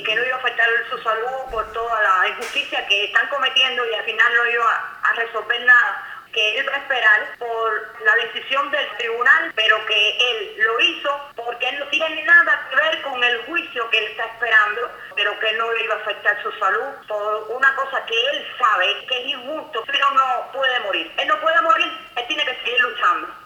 Las declaraciones de la madre del detenido